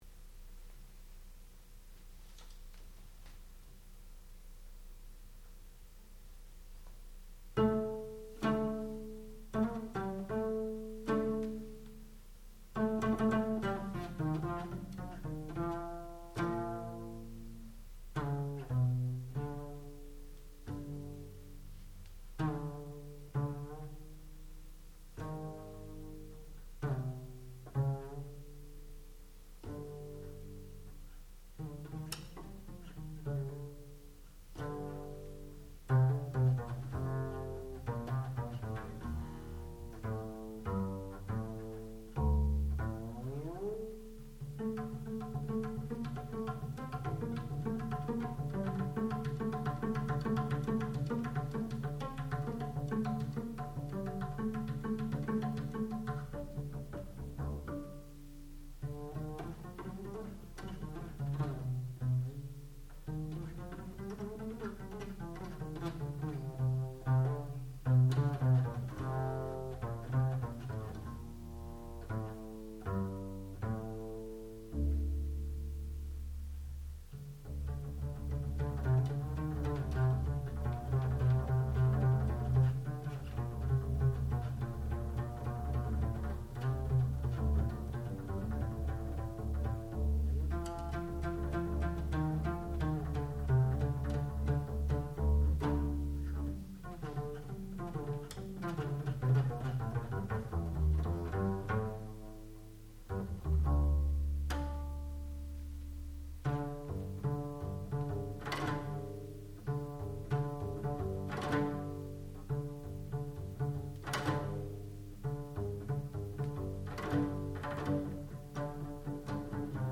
sound recording-musical
classical music
double bass